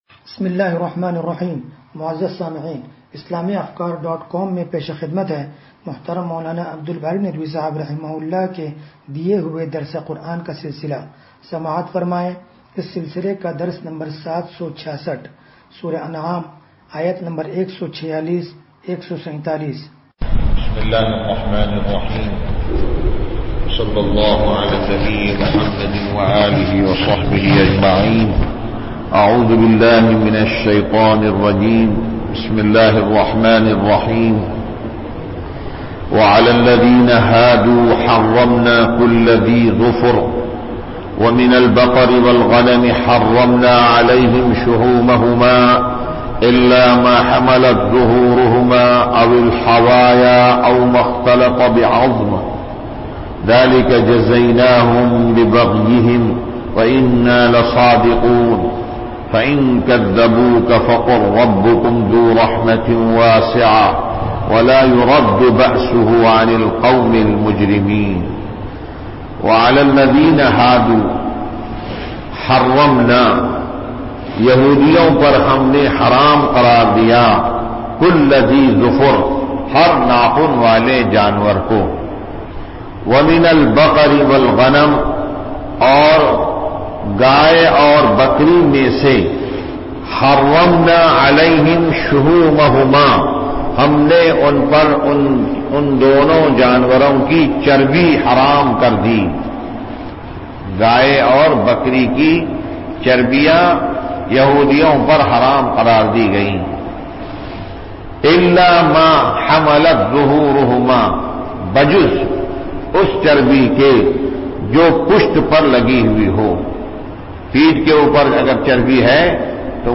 درس قرآن نمبر 0766